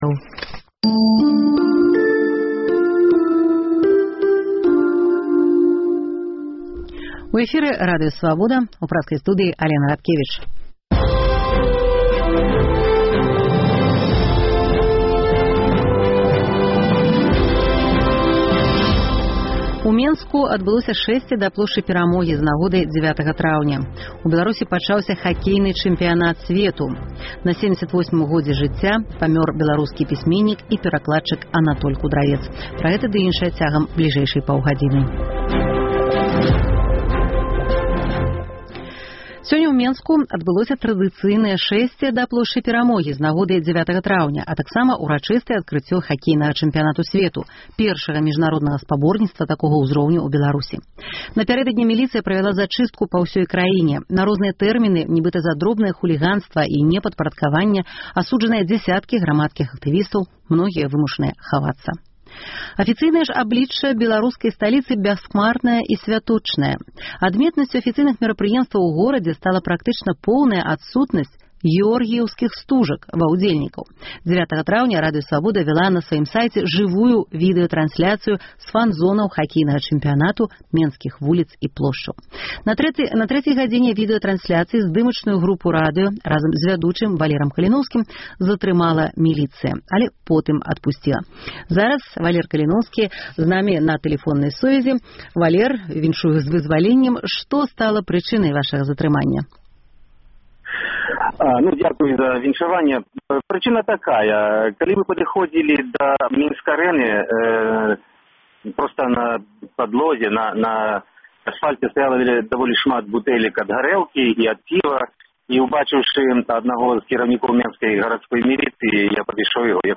Міліцыя не дапусьціла і правядзеньня Рускага маршу. Пра тых, хто сьвяткуе і тых, каму не да сьвята – у жывым эфіры Свабоды.